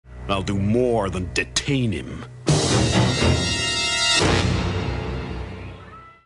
dramatic audio cue backing him up.